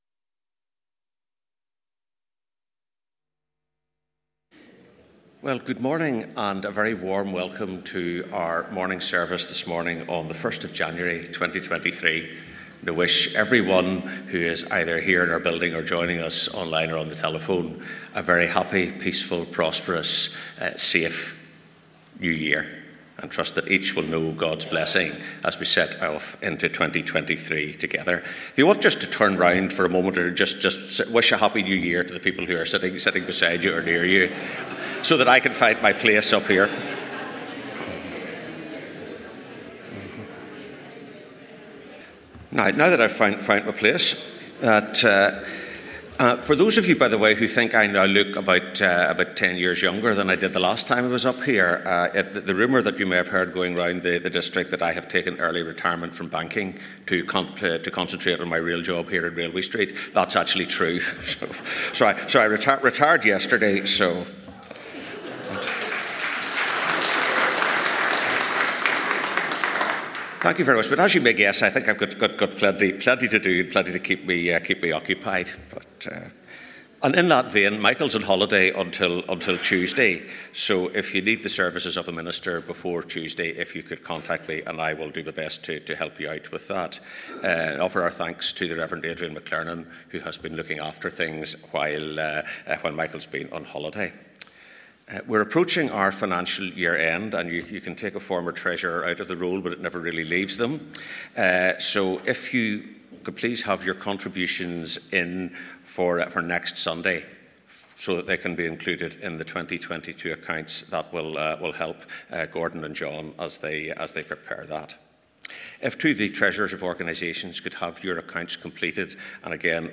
In our service this morning we are going to think about the life of Elijah considering: